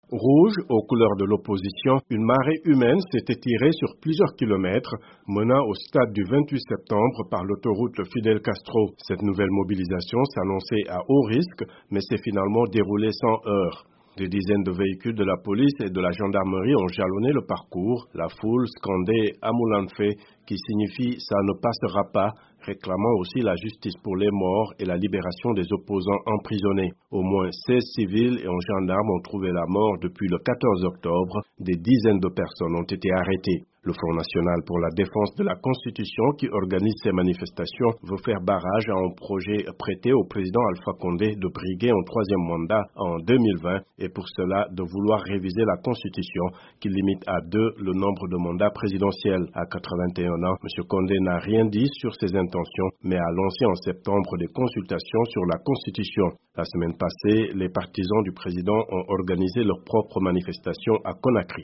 Les Guinéens ont manifesté en masse jeudi dans la capitale, Conakry, contre un éventuel troisième mandat du président Alpha Condé. Tout s’est passé dans le calme. Les manifestants scandaient "Amoulanfe".